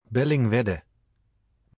Bellingwedde (Dutch pronunciation: [ˌbɛlɪŋˈʋɛdə]
Nl-Bellingwedde.oga.mp3